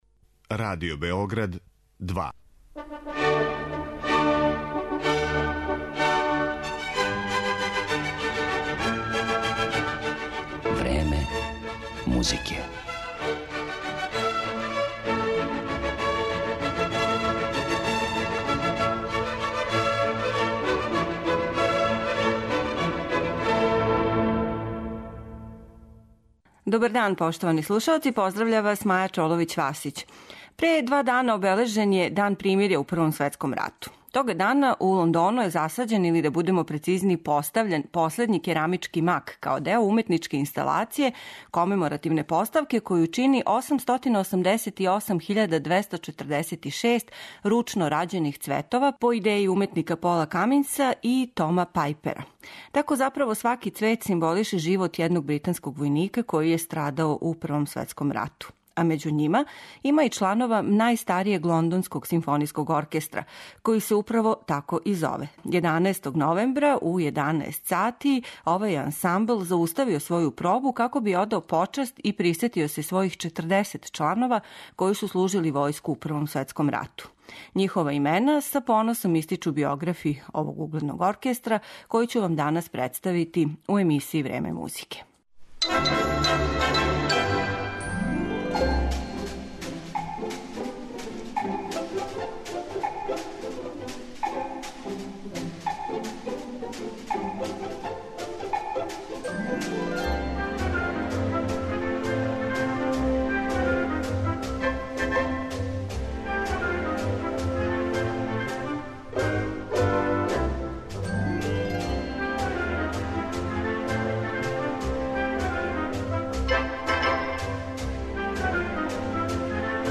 "Време музике" данас доноси музичке фрагменте из дела класичног оркестарског репертоара, али и из партитура музике писане за филм и видео игрице, као и оркестарске верзије великих рок хитова. Све то обједињује један од најпознатијих и најбољих оркестара на свету - Лондонски симфонијски оркестар.